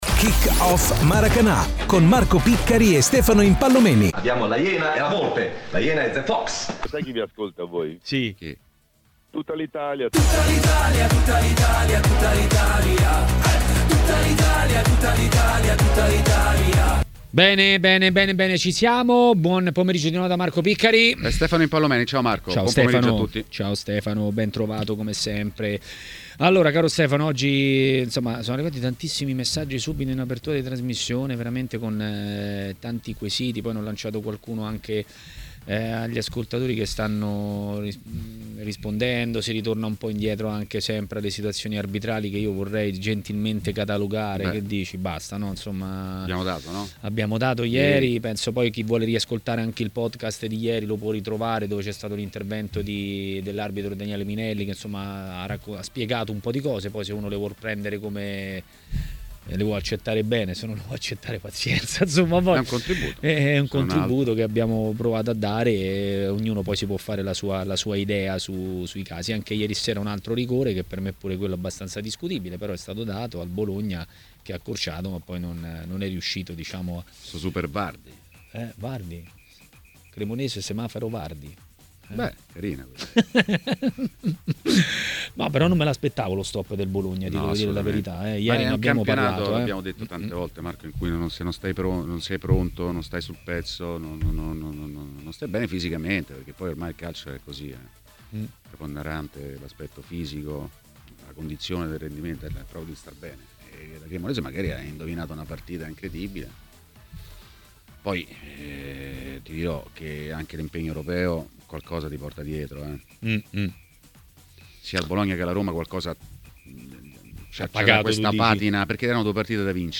L'ex tecnico del Napoli Gigi De Canio è stato ospite di Tmw Radio, durante 'Maracanà'.